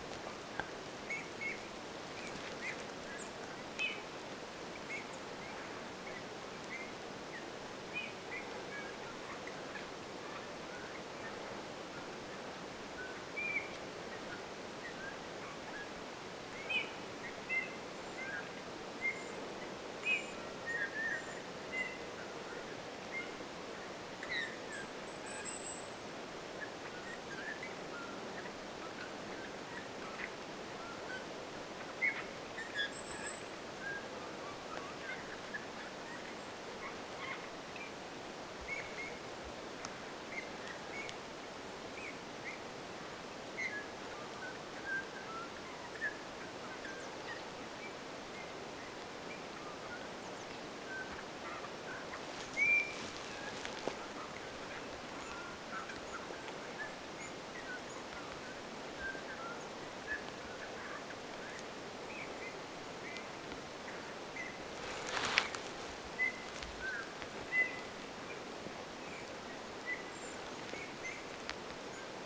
punatulkkujen viheltelyä
punatulkkujen_viheltelya.mp3